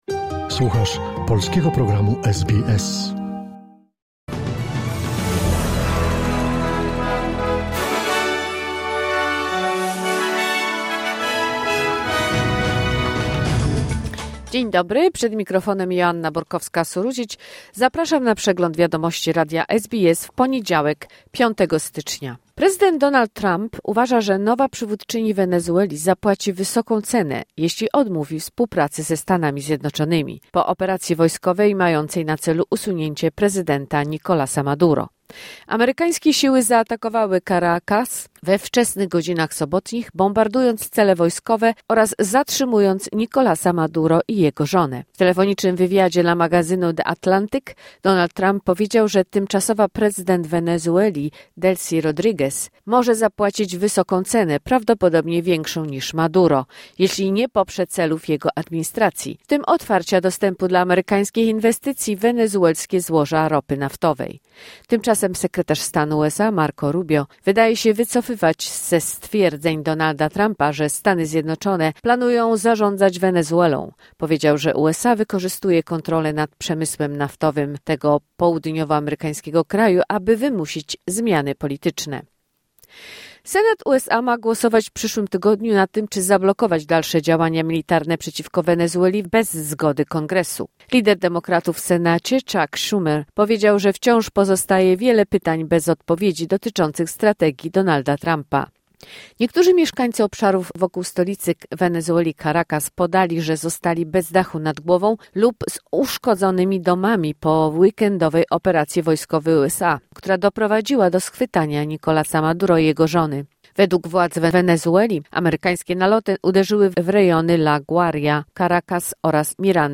Wiadomości 5 stycznia 2026 SBS News Flash